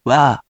We’re going to show you the character, then you you can click the play button to hear QUIZBO™ sound it out for you.
In romaji, 「わ」 is transliterated as「wa」which sounds sort of like 「wahh」or the ‘wa’ from the American pronunciation of ‘water’